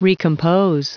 Prononciation du mot recompose en anglais (fichier audio)
Prononciation du mot : recompose